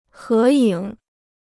合影 (hé yǐng): to take a joint photo; group photo.